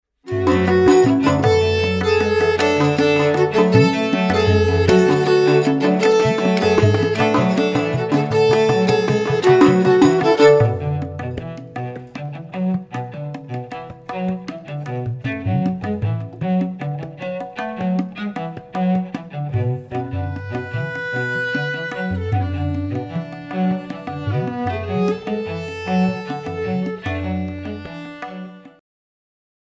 viola
cello